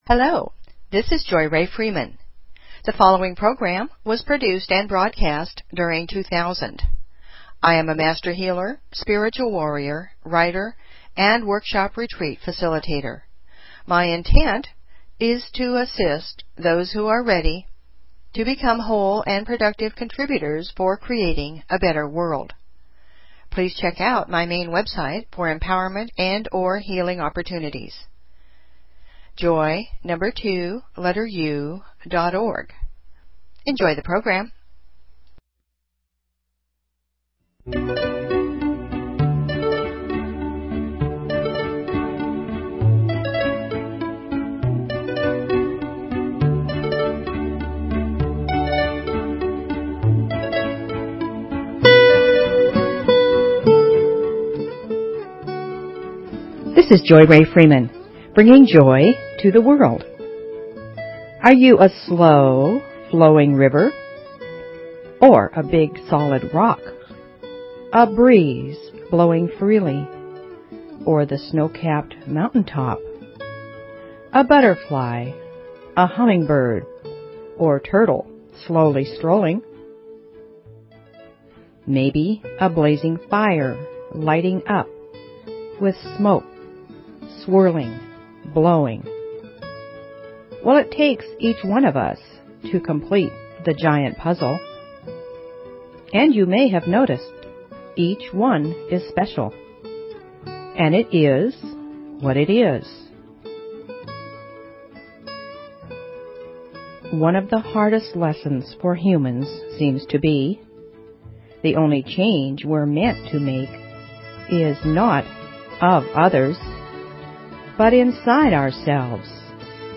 Talk Show Episode, Audio Podcast, Joy_To_The_World and Courtesy of BBS Radio on , show guests , about , categorized as
JOY TO THE WORLD - It's a potpourri of music, INSPIRATION, FOLKSY FILOSOPHY, POETRY, HUMOR, STORY TELLING and introductions to people who are making a difference. It's lively, but not rowdy - it's sometimes serious, but not stuffy - it's a little funny, but not comical - and most of all - it's a passionate, sincere sharing from my heart to yours.